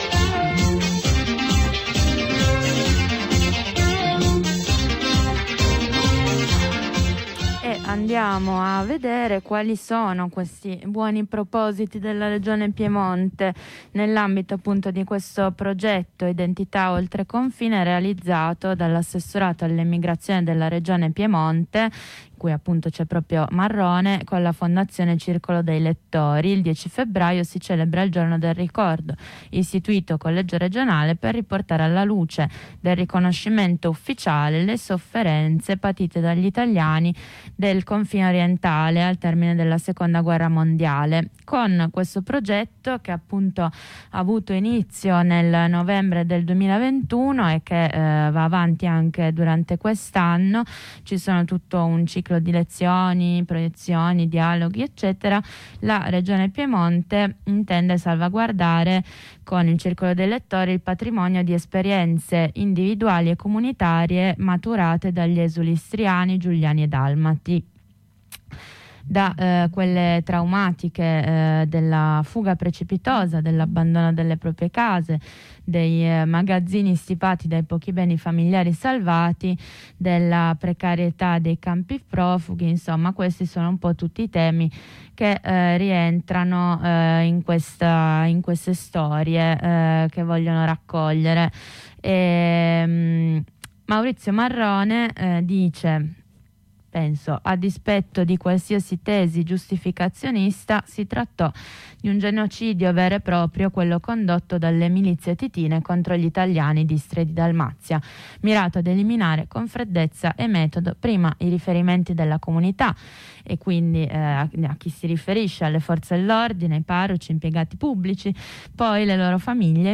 Lo facciamo attraverso la lettura di alcuni pezzi scelti dal libro di Eric Gobetti, “E allora le foibe?”, edito da Laterza (2021).